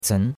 cen2.mp3